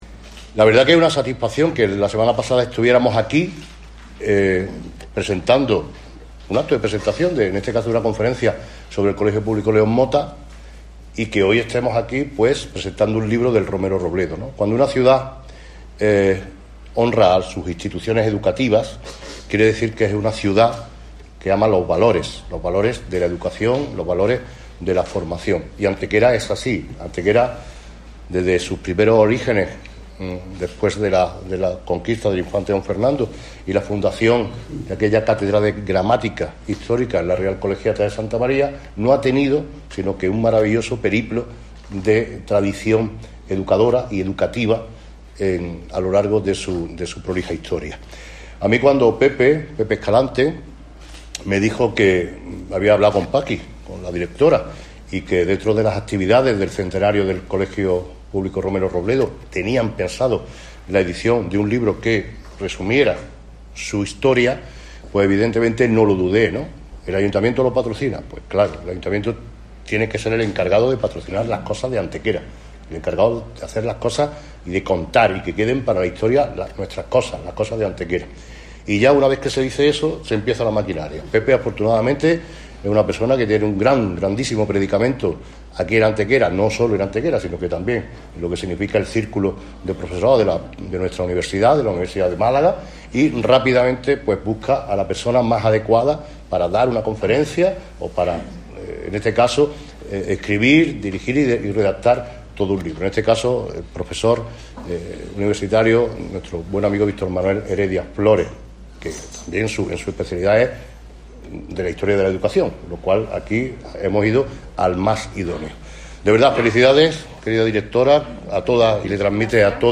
Durante un saludo inicial al comienzo del acto, el Alcalde volvió a reiterar su felicitación en nombre de la ciudad con motivo de este centenario, algo que recordó se hizo patente mediante la concesión de la Medalla de la Jarra de Azucenas el pasado 16 de septiembre, "hecho que os debe de llenar de orgullo al ser, junto al León Motta, los primeros colegios en recibirla".
Cortes de voz